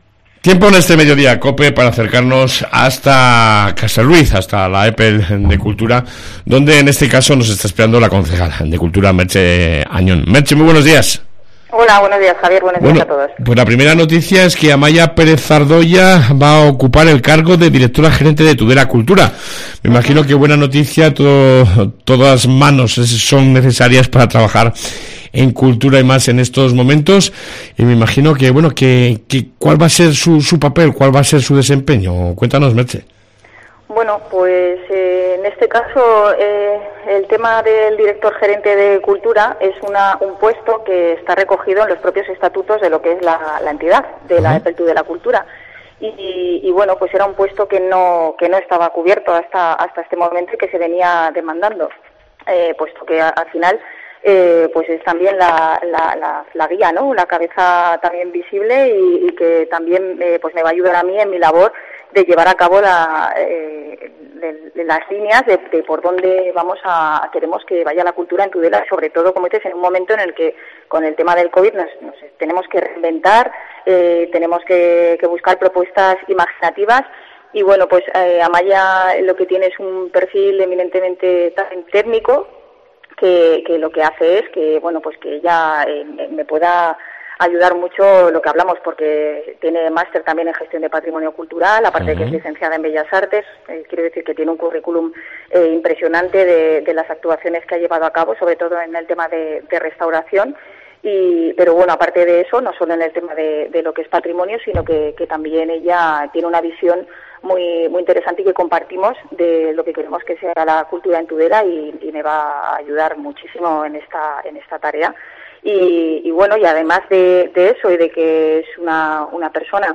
AUDIO: Entrevista con la Concejal de Cultura de Tudela Merche Añon